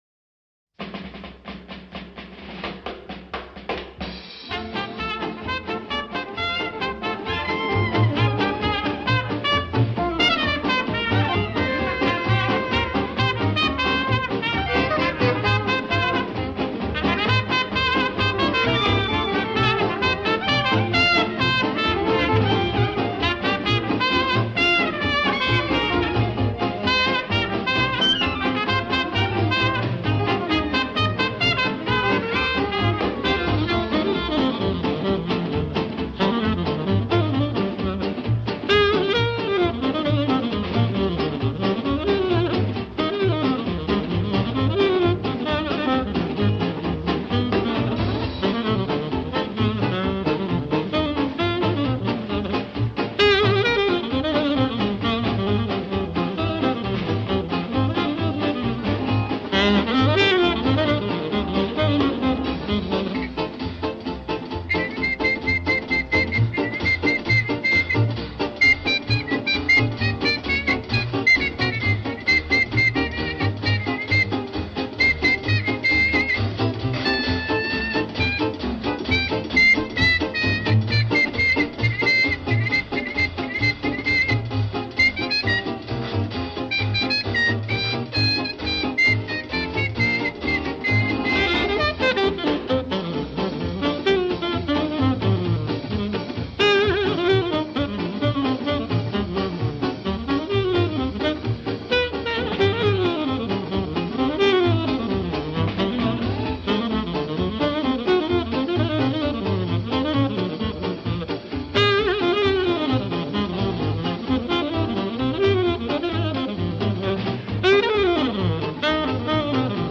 Jazz After Dark spans the gamut, from roots in boogie-woogie, blues, and ragtime through traditional and straight-ahead jazz, soul jazz, bossa nova, and more.